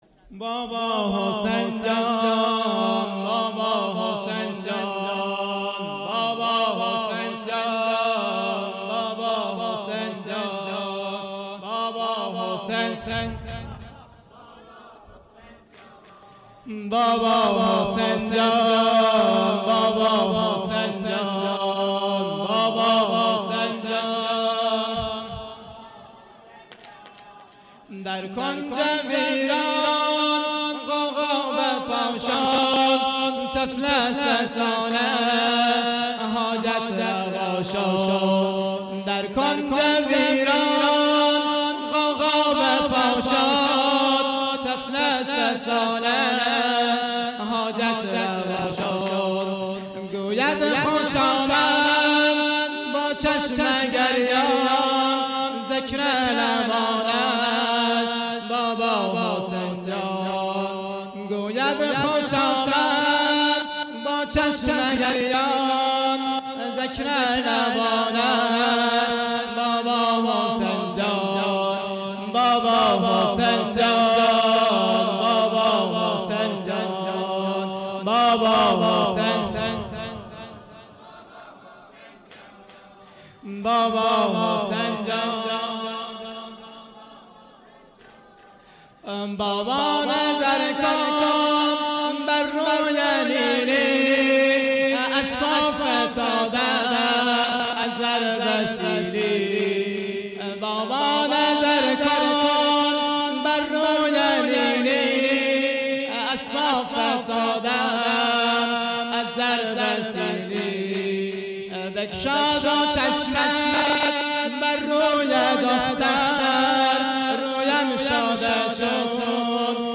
شب سوم محرم98 هیئت میثاق الحسین (ع) سیستان